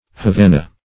Havana \Ha*van"a\ (h[.a]*v[a^]n"[.a]), prop. a.